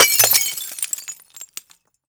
glass_fall04hl.ogg